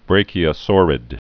(brākē-ə-sôrĭd, brăkē-)